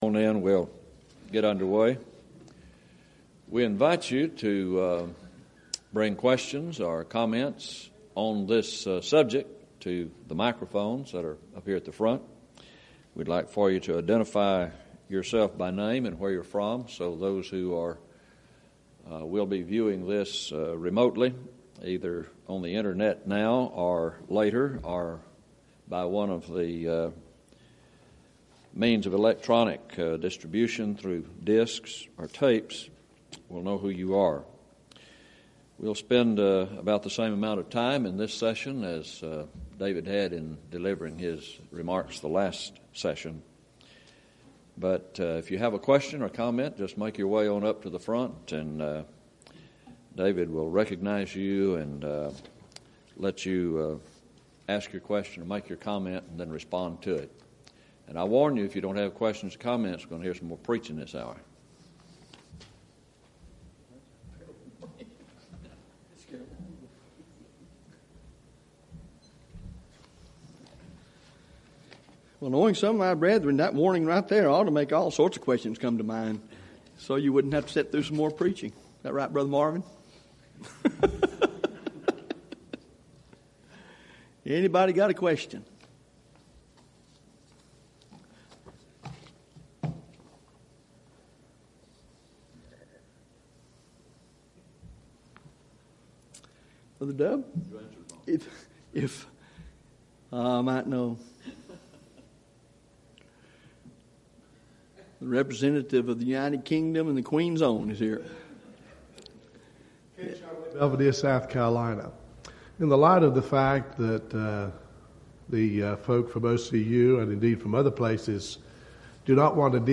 Event: 2nd Annual Schertz Lectures
lecture